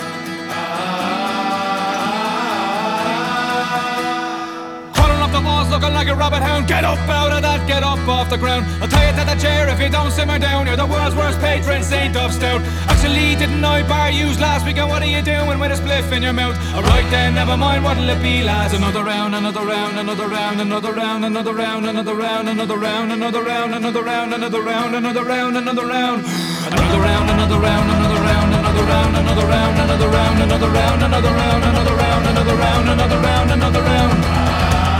Жанр: Рок / Фолк